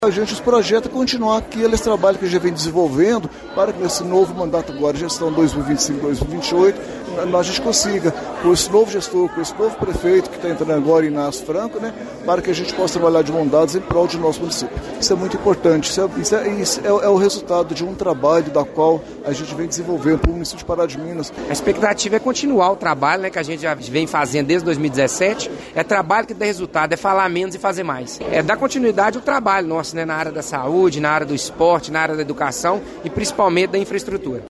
Em conversa com o Jornal da Manhã, os vereadores se mostraram animados com o começo dos trabalhos.